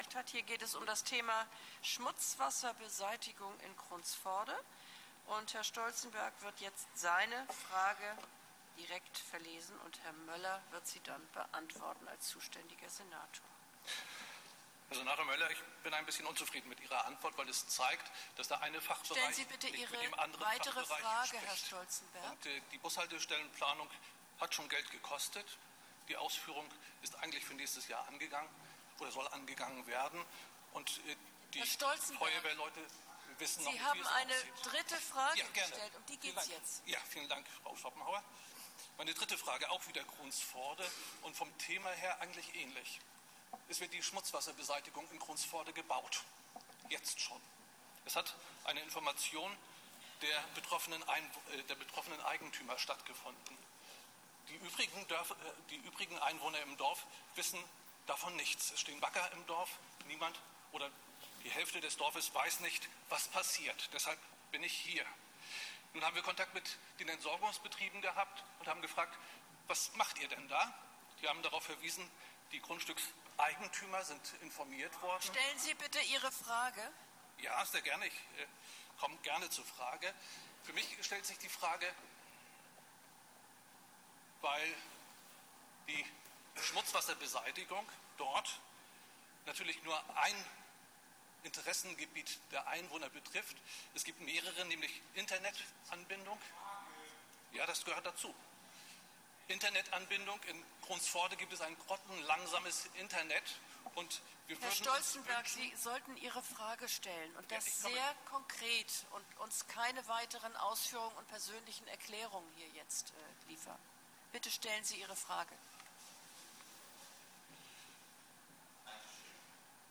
Als Einwohner des Stadtbezirks Kronsforde habe ich in der Einwohnerfragestunde in der Bürgerschaftssitzung am 20. Juni 2013 Einwohnerfragen gestellt.